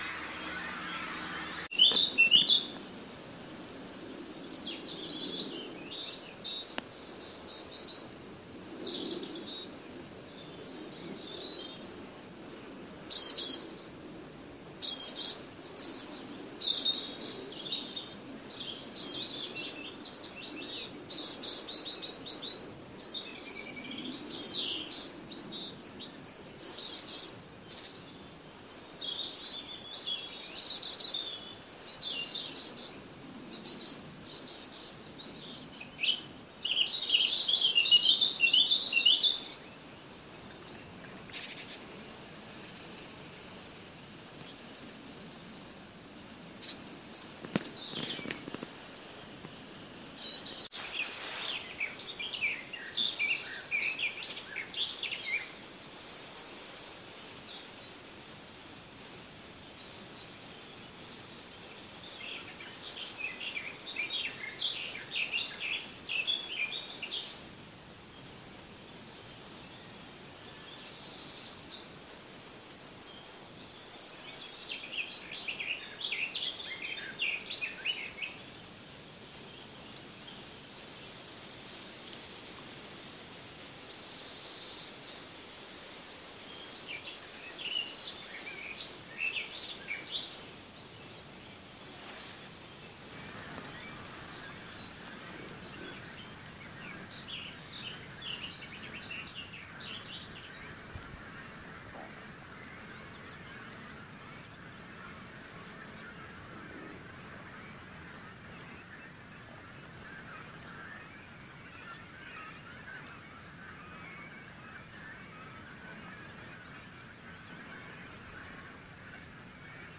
Je kunt luisteren naar vogelgeluiden, opgenomen 30 mei 2014 in de duinen van Oostvoorne.
vogels.mp3